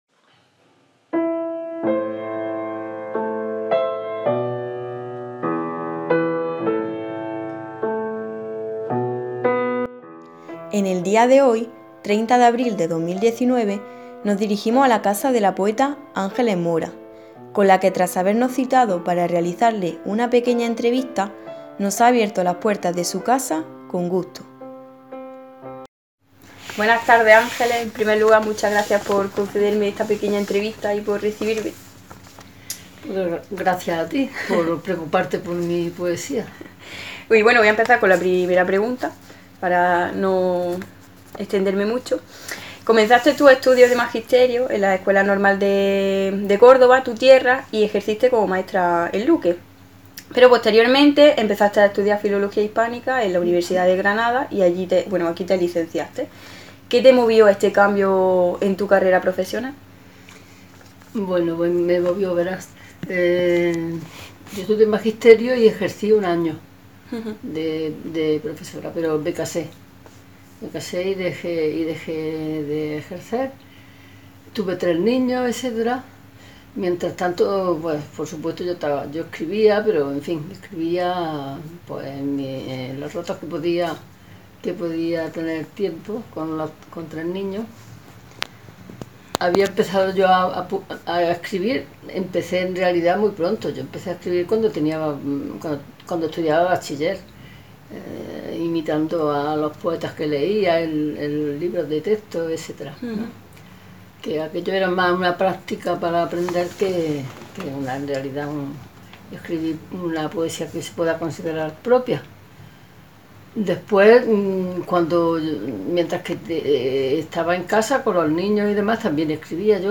Entrevista-Ángeles-Mora.m4a